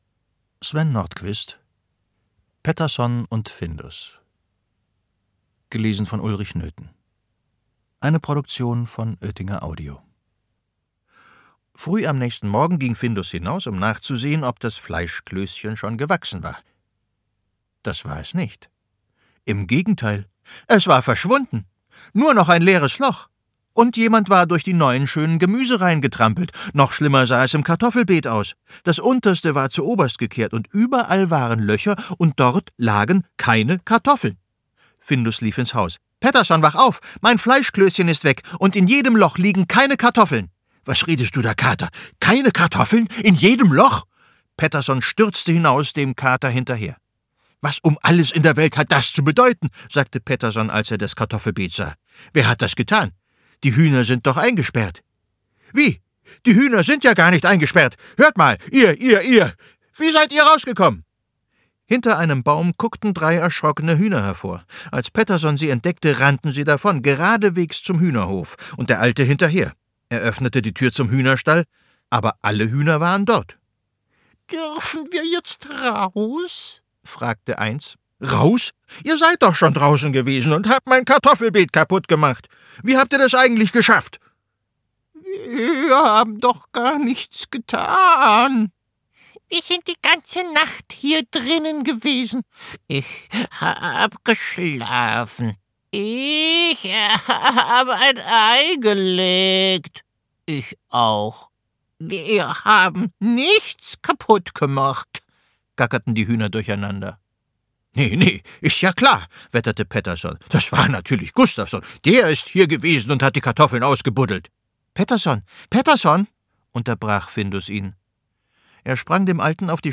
Ulrich Noethen (Sprecher)